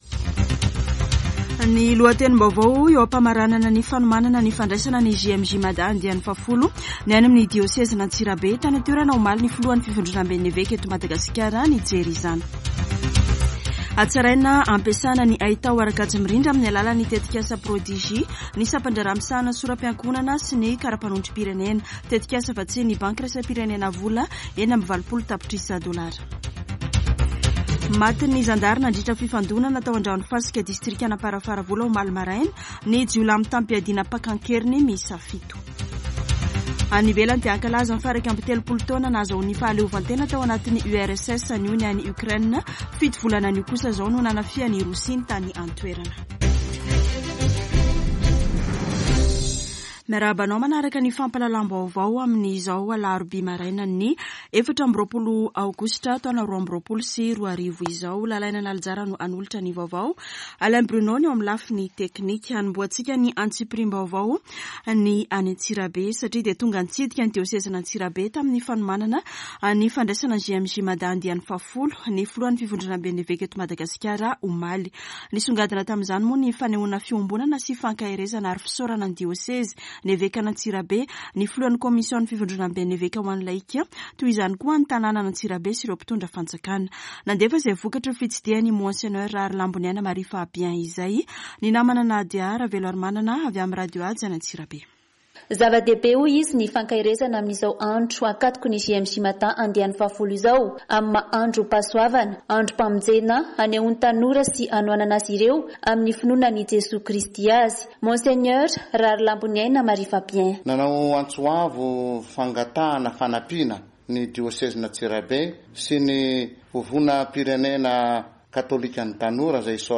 [Vaovao maraina] Alarobia 24 aogositra 2022